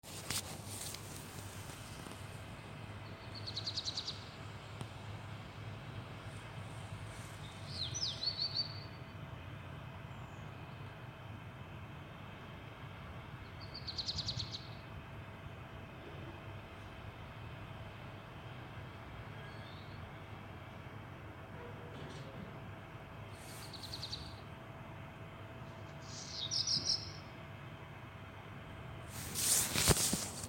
Птицы -> Дроздовые ->
горихвостка-чернушка, Phoenicurus ochruros
СтатусПоёт